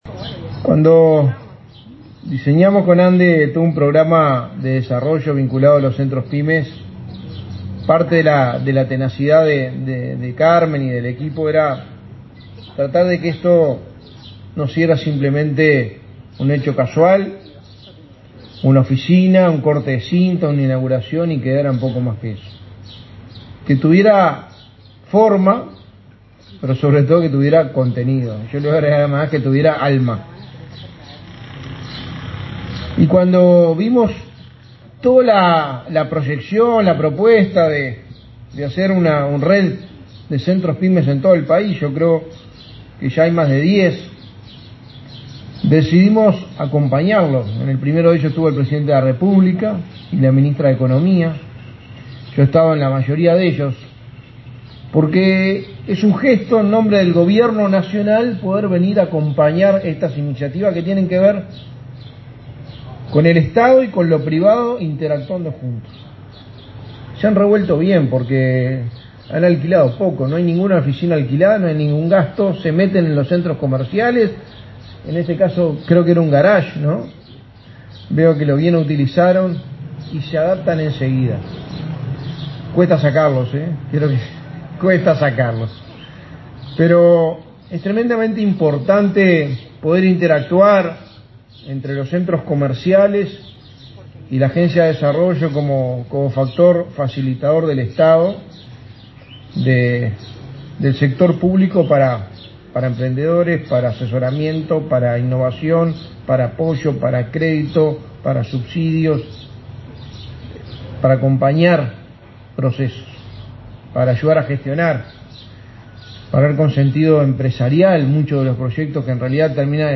Palabras del secretario de Presidencia, Álvaro Delgado
Este viernes 10, el secretario de Presidencia, Álvaro Delgado, participó de la inauguración de un centro Pyme en Mercedes.